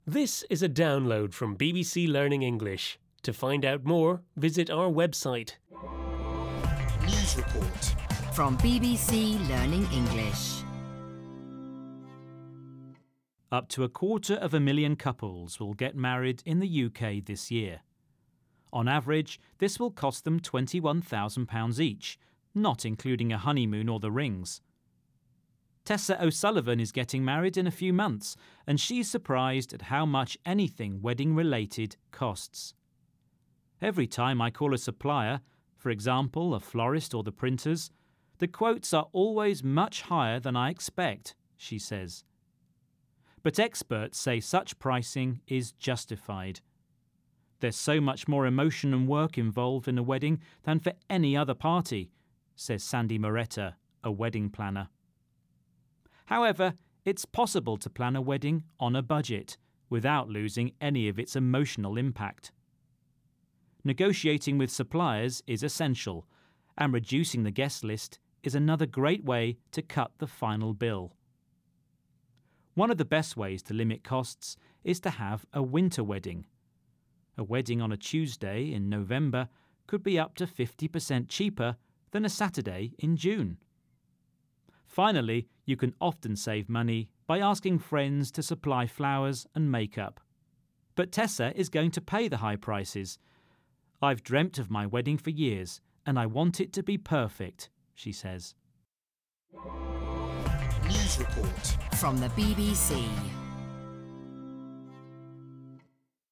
unit-9-3-1-U9_s4_news_report_download.mp3